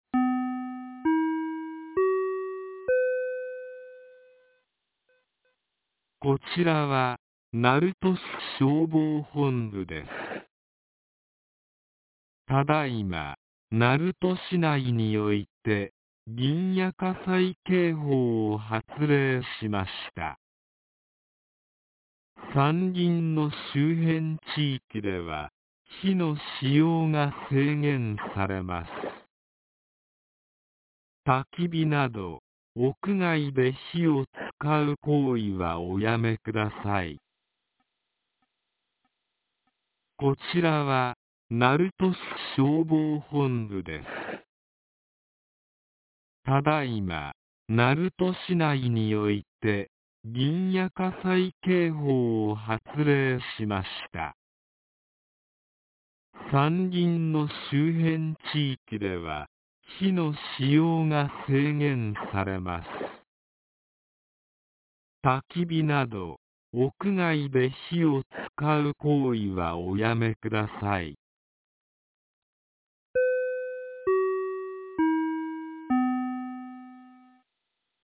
2026年01月24日 08時01分に、鳴門市より鳴門町へ放送がありました。